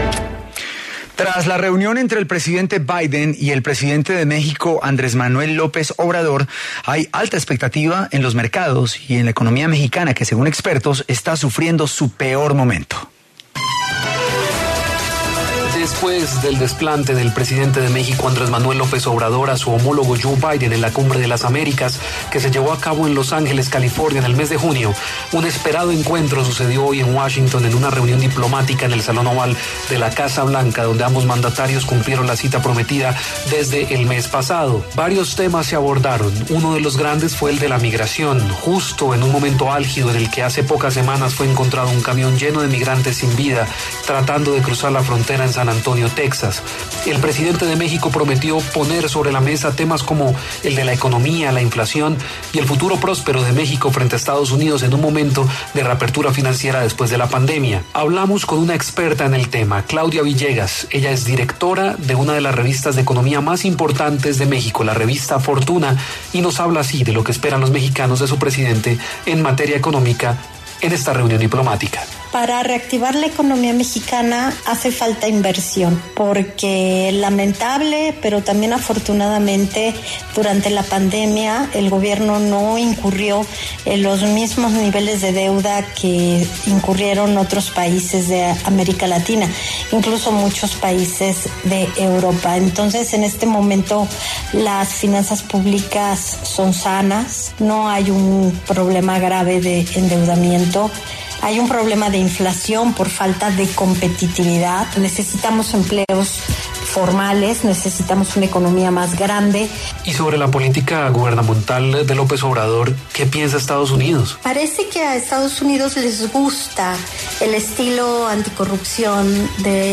Hablamos con una experta en el tema